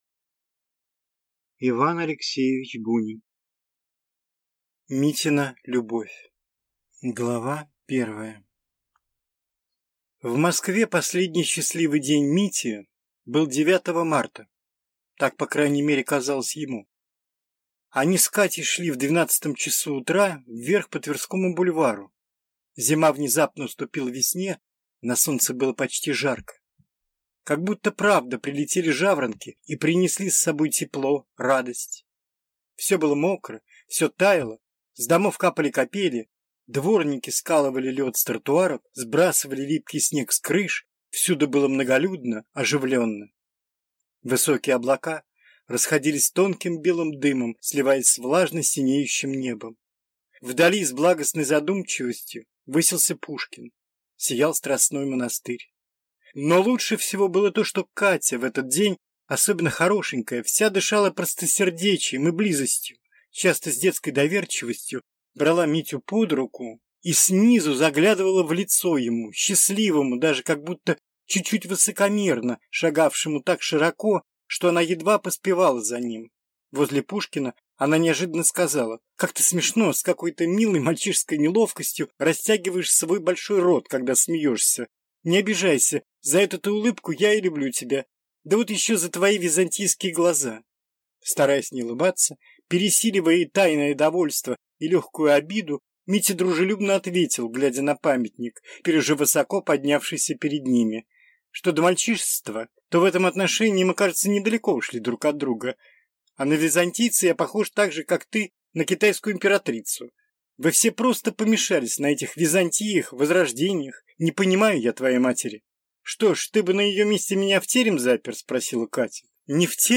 Аудиокнига Митина любовь | Библиотека аудиокниг